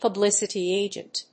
アクセントpublícity àgent